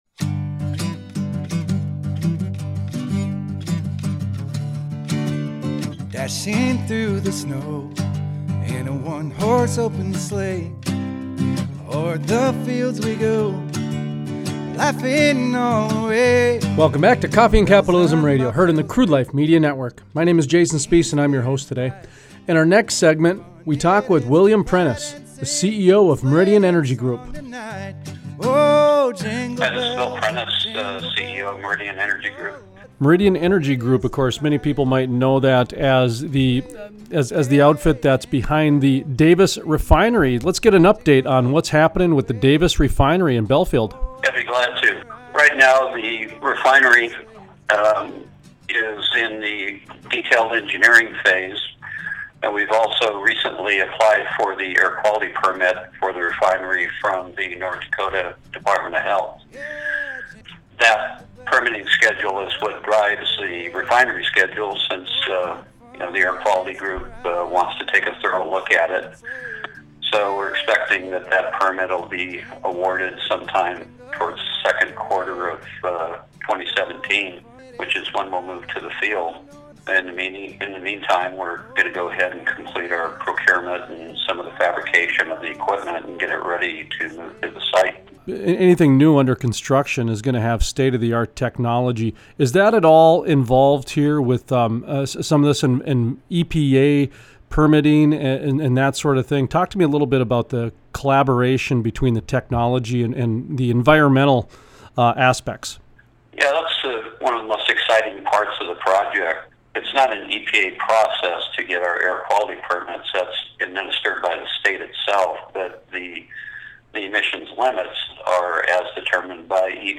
Interviews